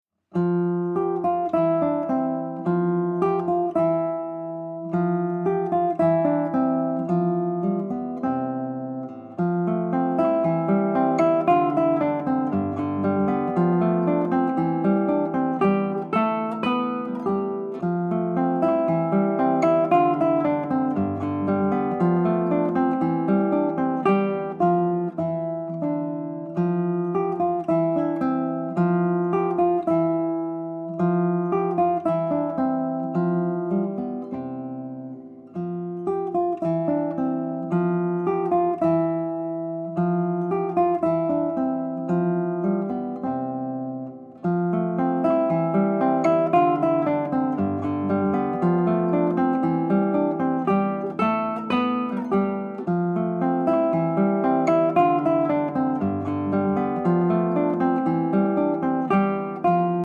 Collezione di studi per chitarra
chitarrista